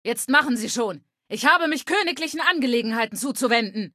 Datei:Femaleadult01default ms02 greeting 000ac044.ogg
Fallout 3: Audiodialoge